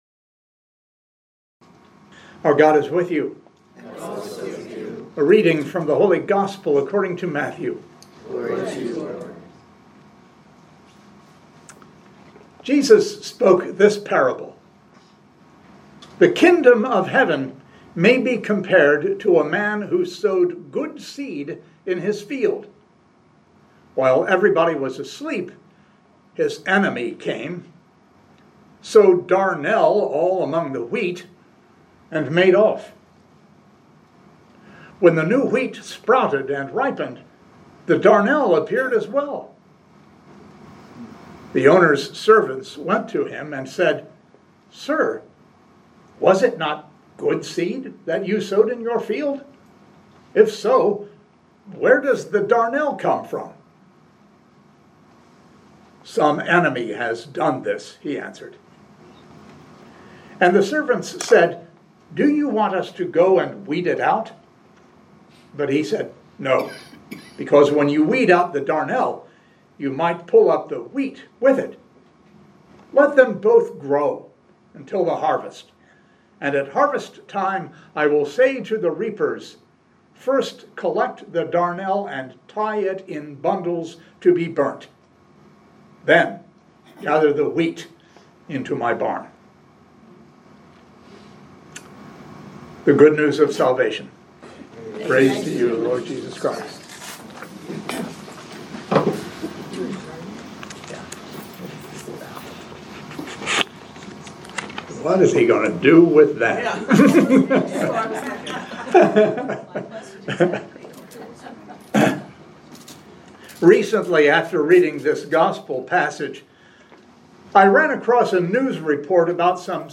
Living Beatitudes Community Homilies: Get Real and Get Patient